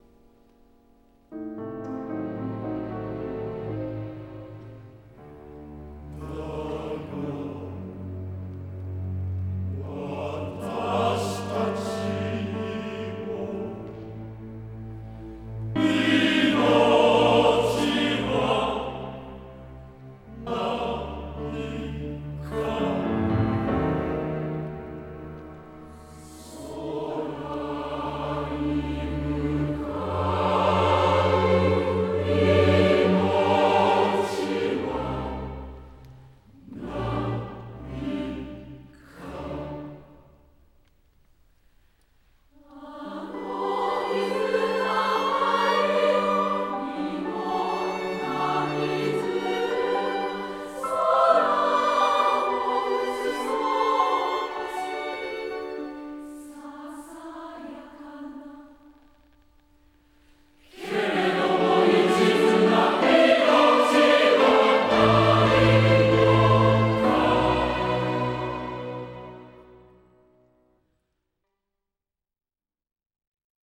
弦楽とピアノ伴奏版